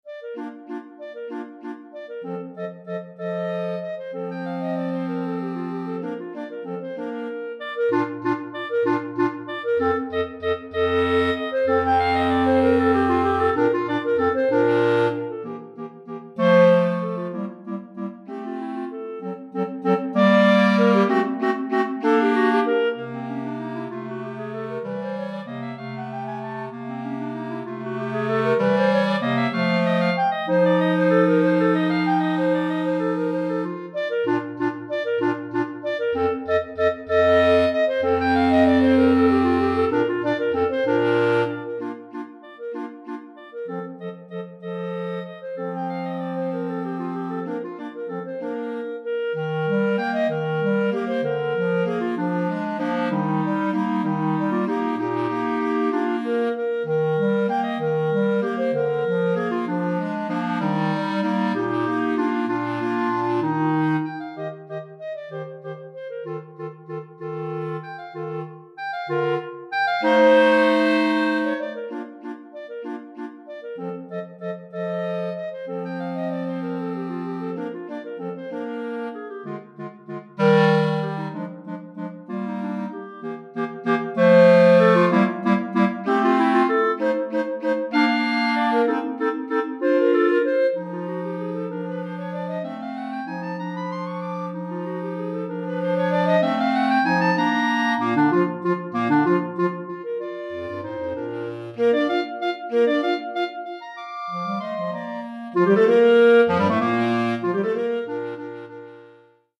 2 Clarinettes en Sib et Clarinette Basse